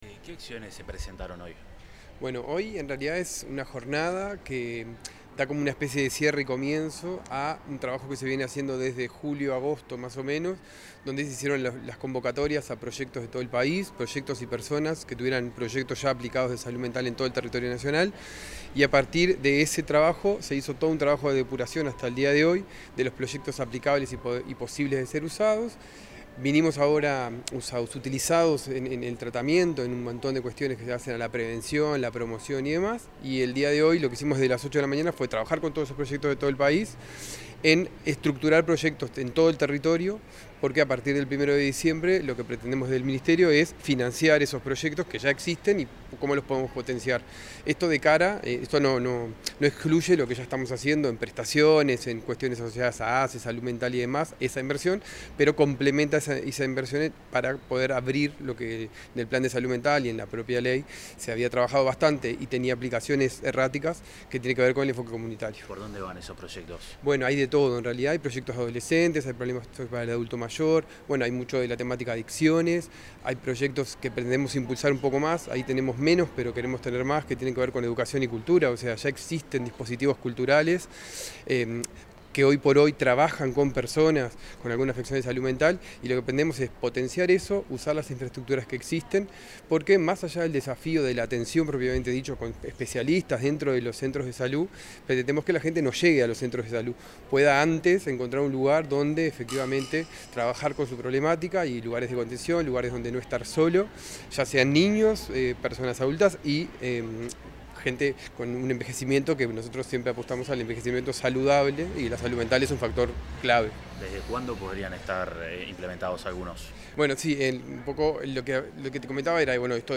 Declaraciones del director general del MSP, Rodrigo Márquez
Tras el encuentro, se expresó al respecto el director general del Ministerio de Salud Pública, Rodrigo Márquez.